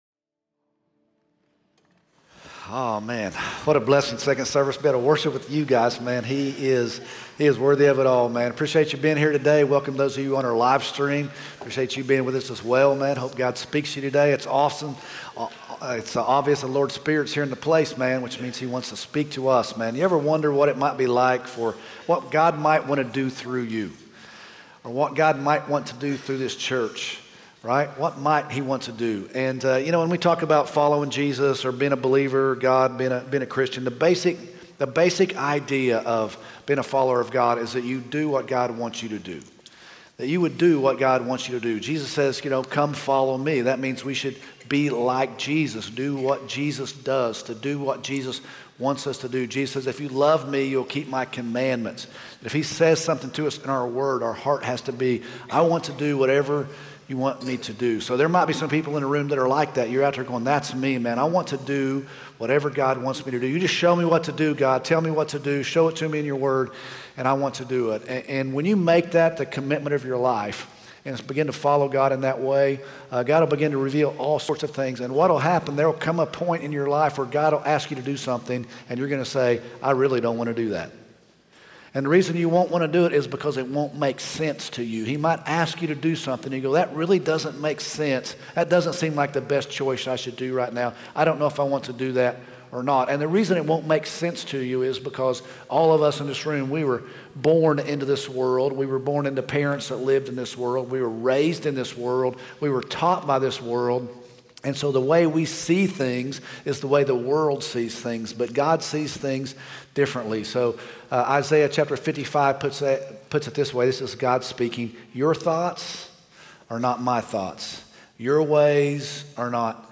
Listen on Spotify In this sermon: Essence of Faith and Obedience: Following Jesus and doing what God wants us to do. Biblical examples of faith from Peter and Abraham. Lessons from Joshua: Crossing the Jordan and entering the Promised Land.